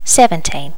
Update all number sounds so they are more natural and remove all clicks.